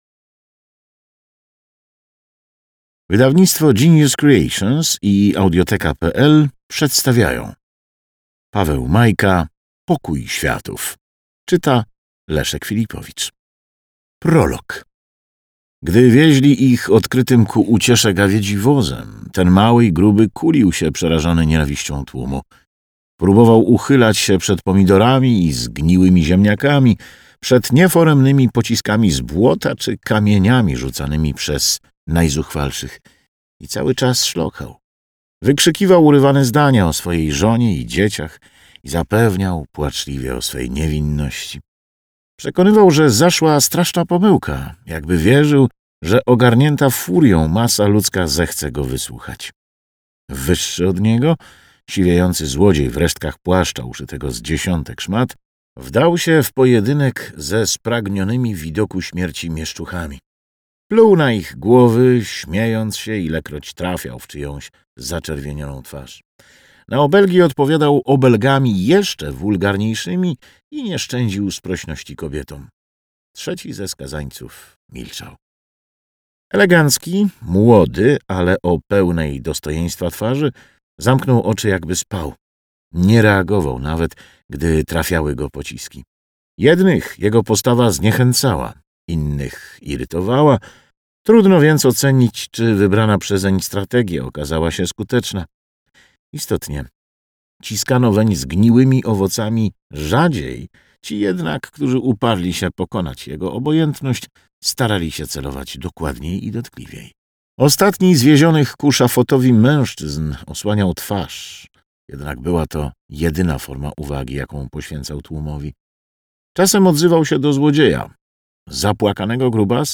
Na zachętę poniżej znajdziecie prolog powieści w formie dźwiękowej.